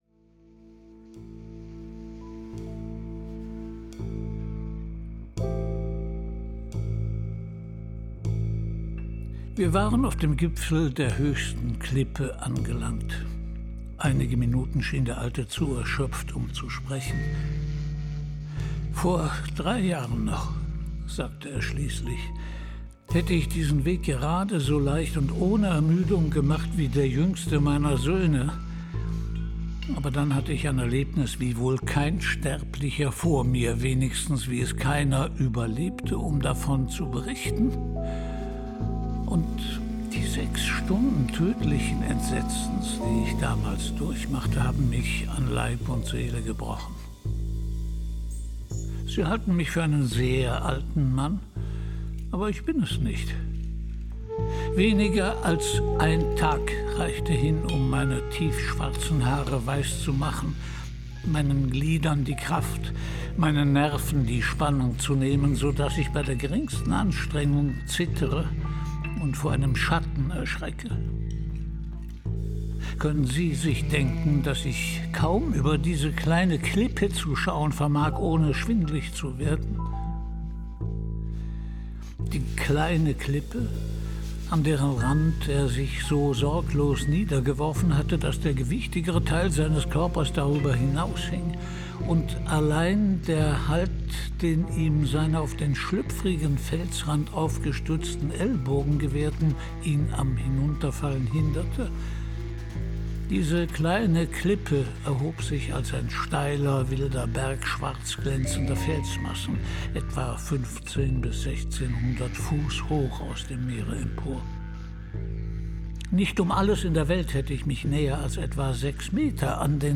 Hinab in den Maelström Edgar Allan Poe (Autor) Christian Brückner (Sprecher) Audio-CD 2023 | 1.